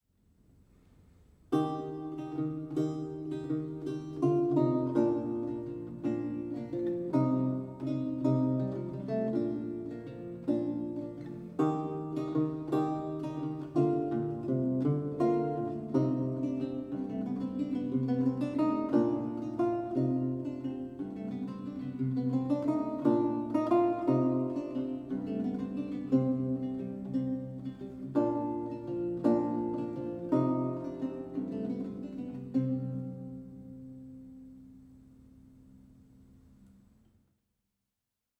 Audio recording of a lute piece from the E-LAUTE project